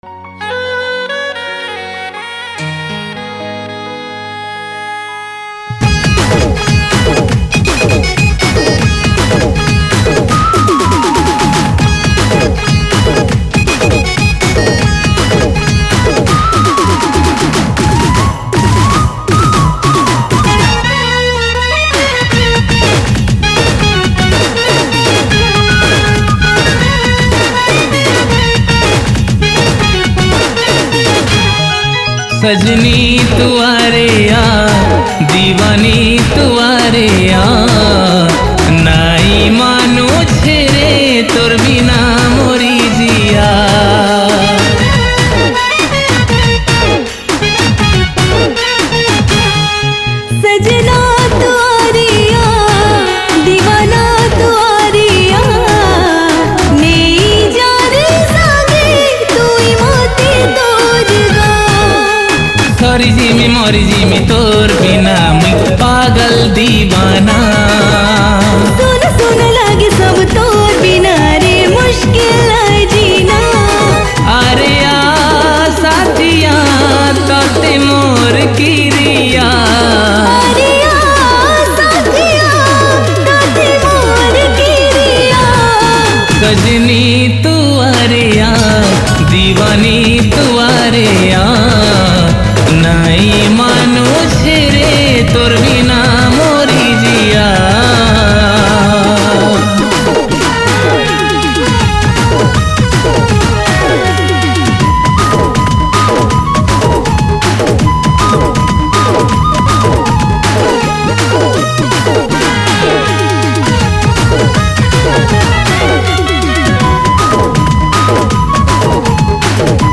Sambapuri Single Song 2021